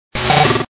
Fichier:Cri 0171 DP.ogg
contributions)Televersement cris 4G.